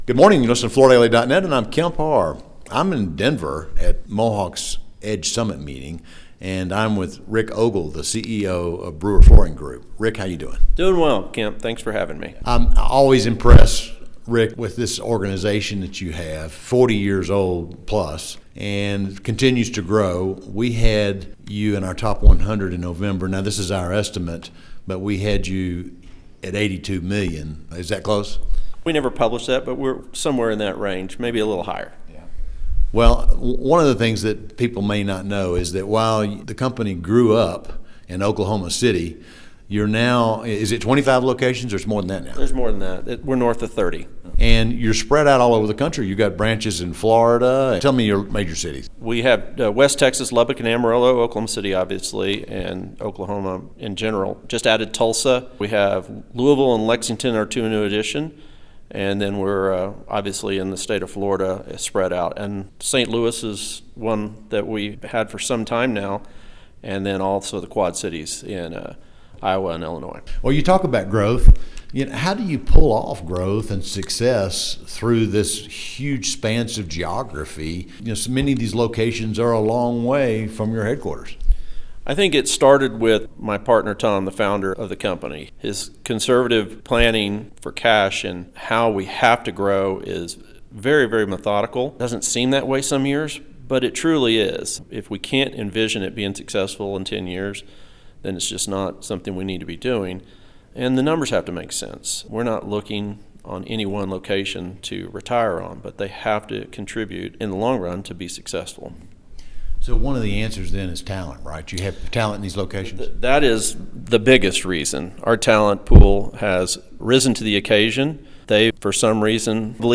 Listen to the interview, taped at Mohawk’s Edge Summit, for more details.